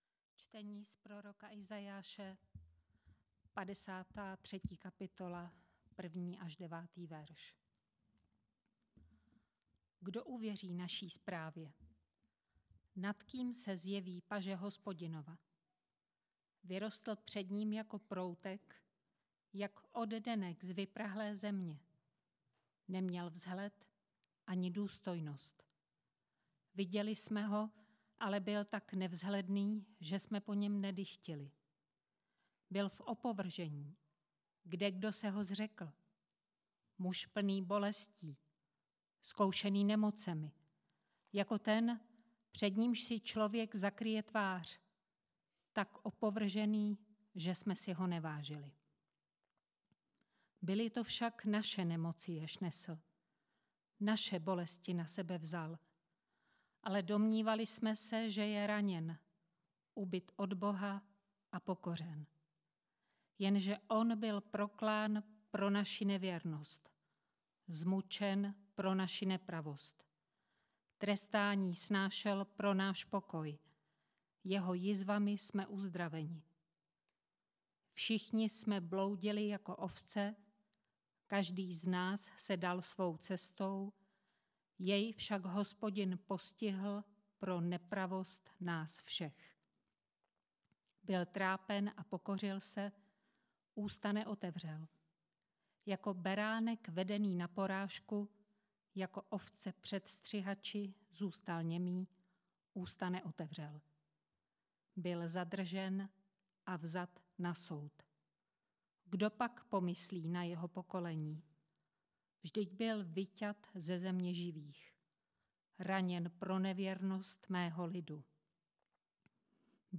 Velkopáteční bohoslužba Izajáš 53, 1 - 9 Marek 15, 33 - 47